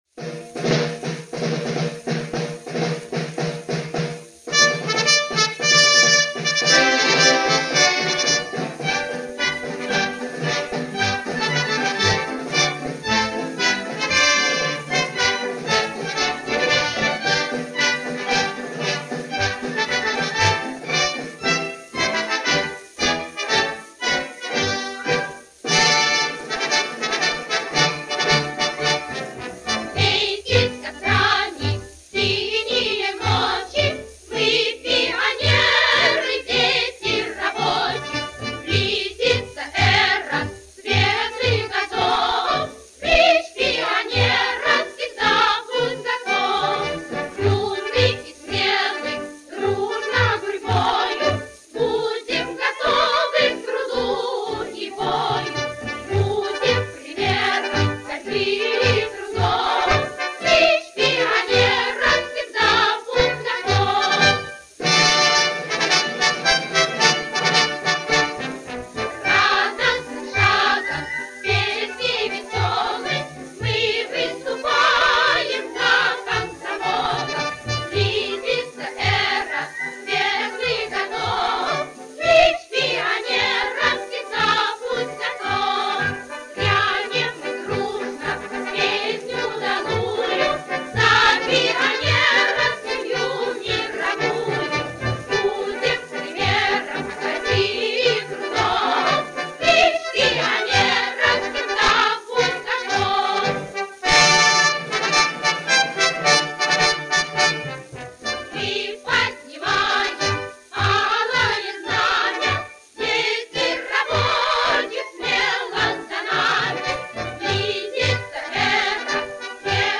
Sovetskiq_Detskiq_hor_Pionery.mp3